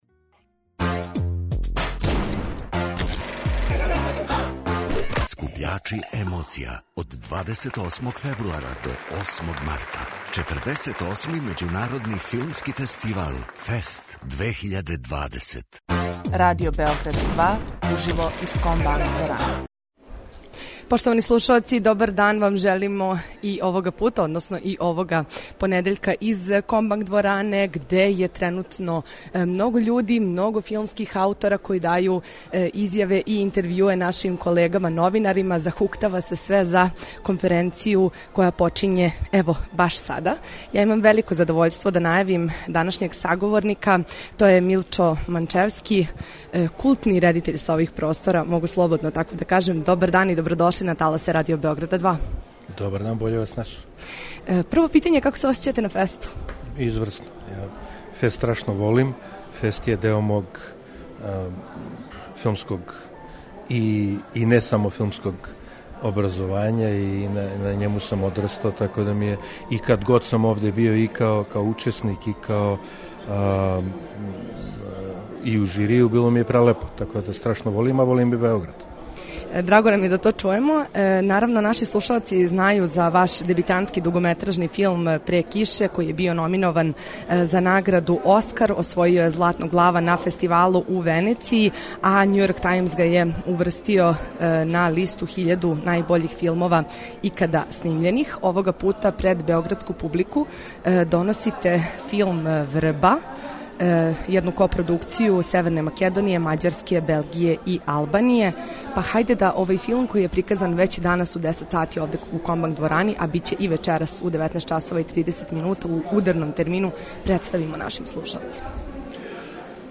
Данашњи гост емисије је романописац, есејиста и драмски писац Џевад Карахасан.